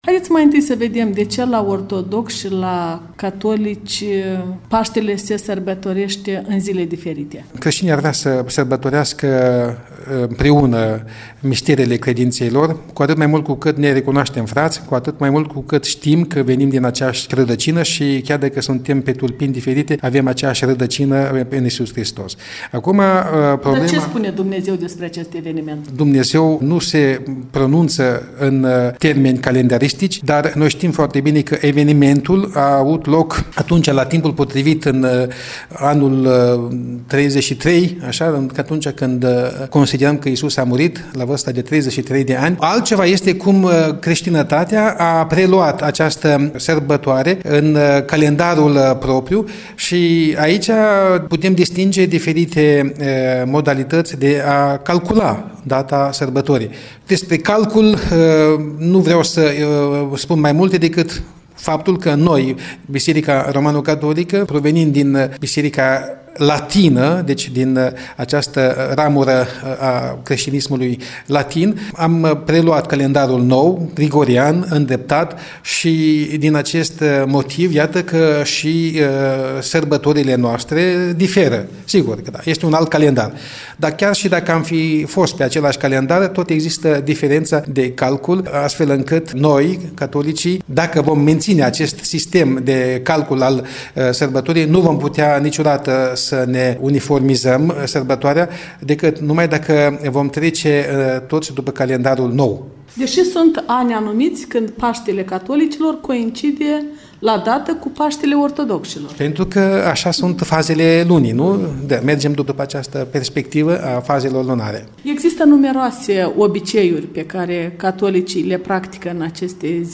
Un interviu cu Monseniorul Anton Coșa cu ocazia sărbătorii Paștelui romano-catolic